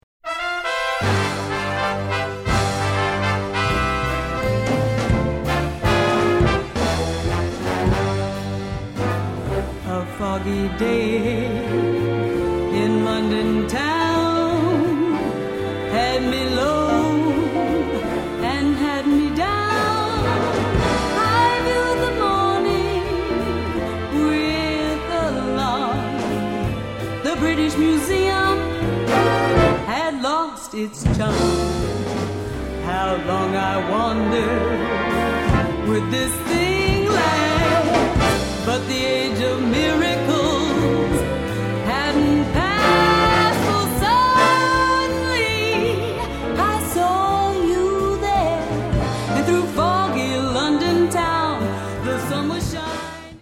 full 27-piece orchestra, 17-piece dance band